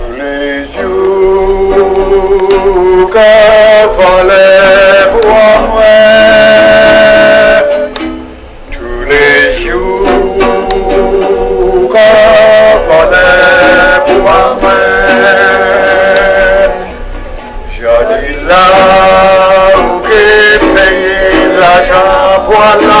This tape is a record of a cross-section of the proceedings of the programme for Creole Day, put on by the Department of Language and Linguistics, Faculty of Arts and General Studies, The University of the West Indies, St. Augustine.
l audio cassette